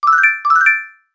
talk_ringing.wav